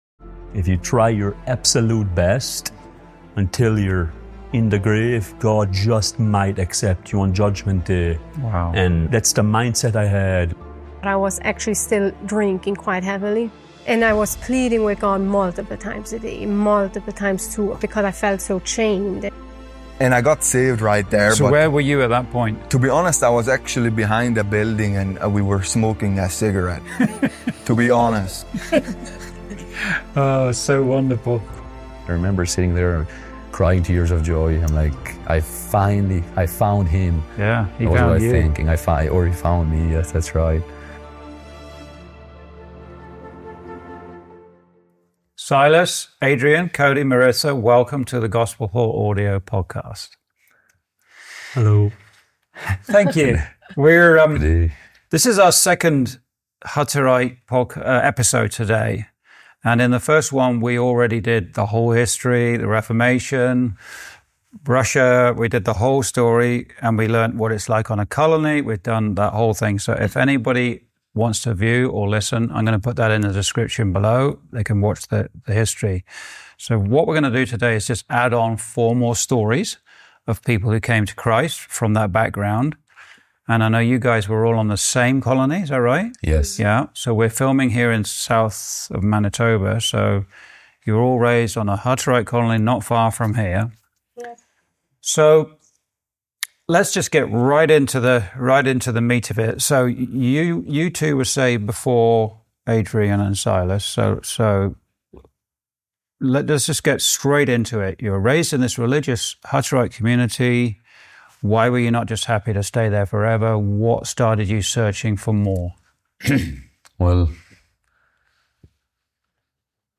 Testimonies
Recorded in Manitoba, Canada, 24th Feb 2026